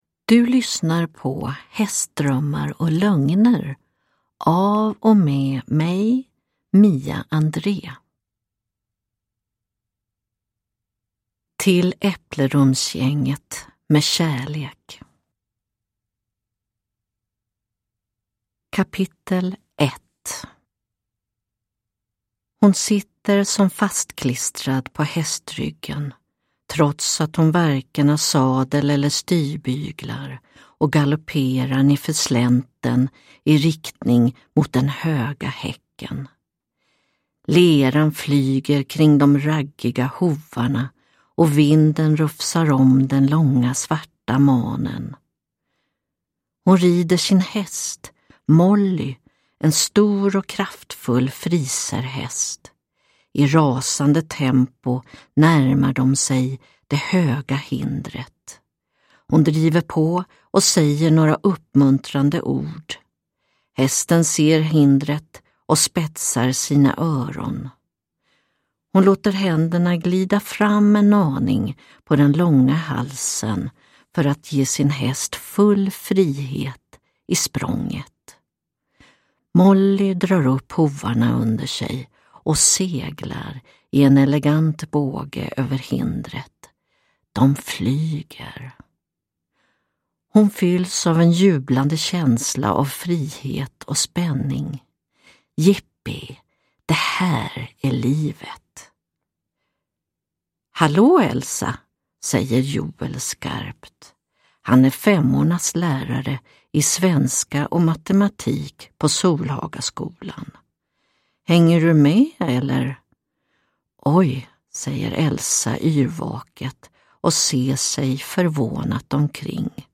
Hästdrömmar och lögner (ljudbok) av Mia André